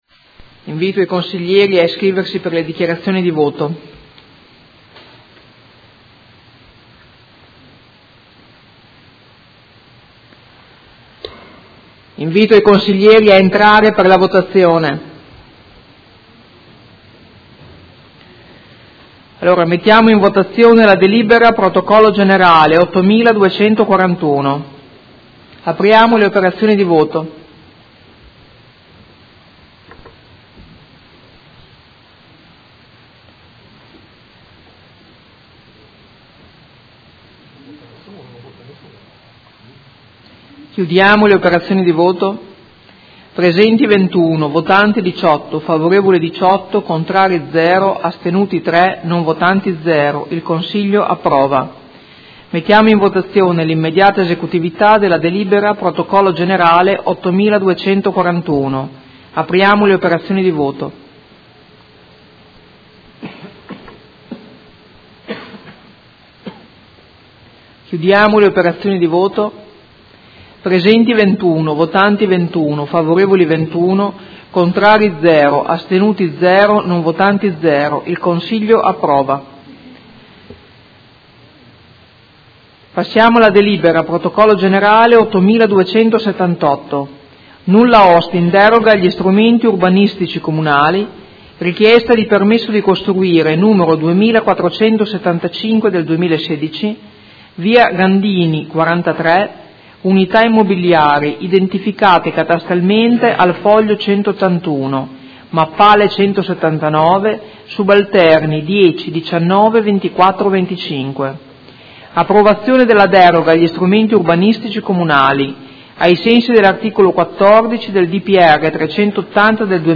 Presidente — Sito Audio Consiglio Comunale
Seduta del 2/2/2017.